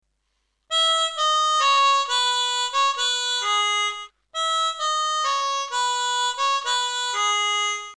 And for an E major chromatic (I’m using a Hohner CX12)